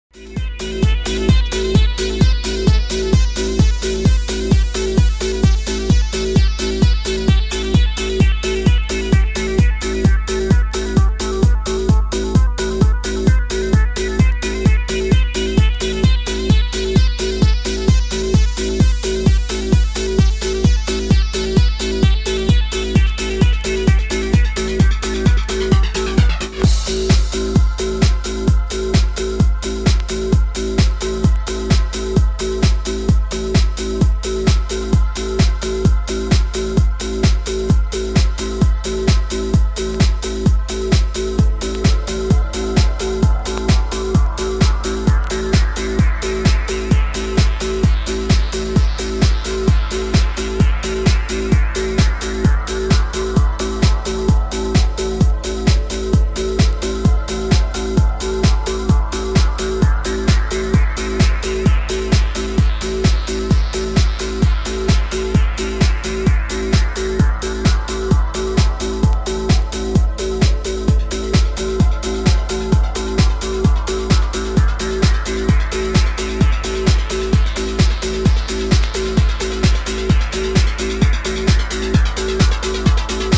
1996 progressive trance